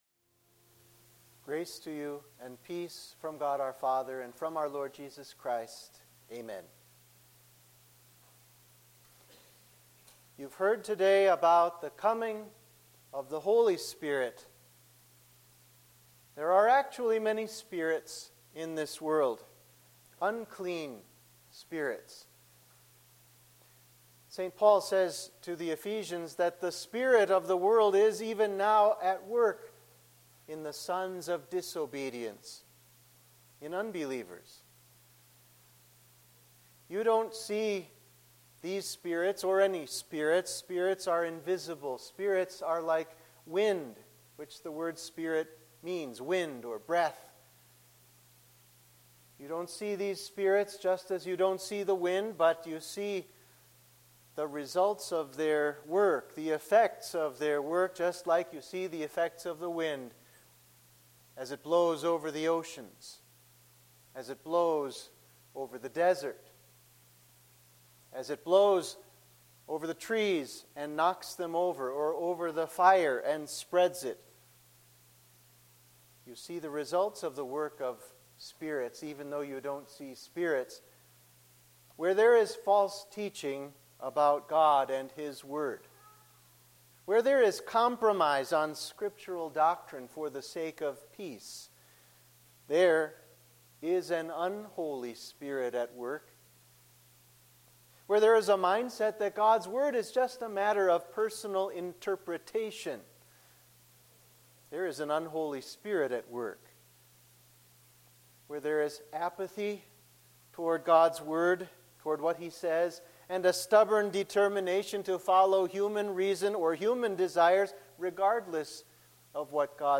Sermon for the Day of Pentecost